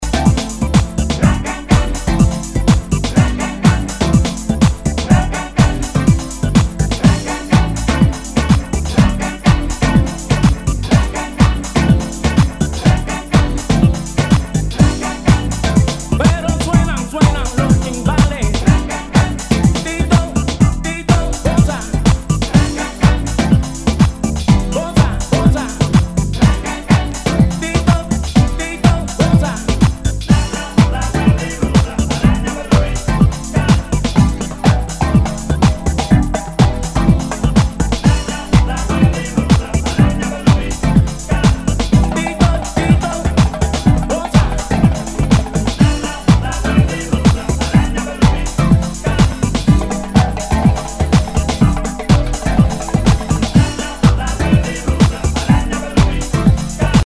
HOUSE/TECHNO/ELECTRO
ナイス！ディープ・ハウス・クラシック！
全体にチリノイズが入ります